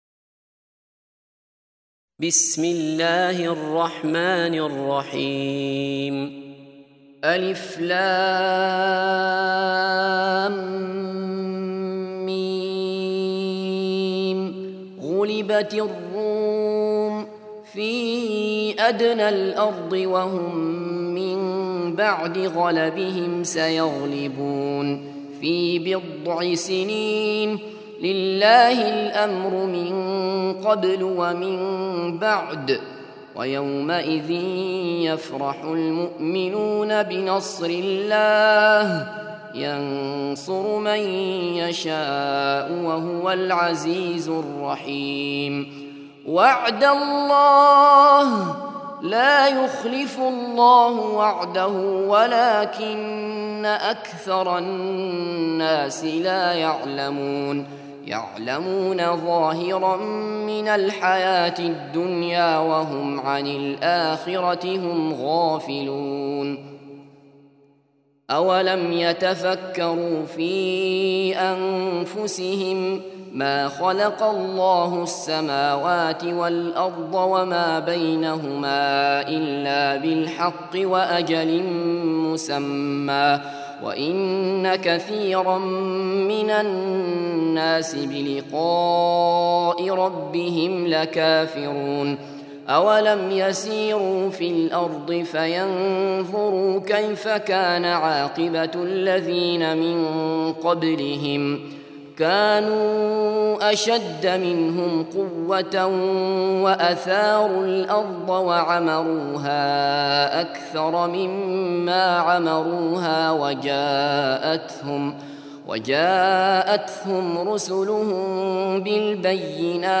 30. Surah Ar�R�m سورة الرّوم Audio Quran Tarteel Recitation
Surah Repeating تكرار السورة Download Surah حمّل السورة Reciting Murattalah Audio for 30.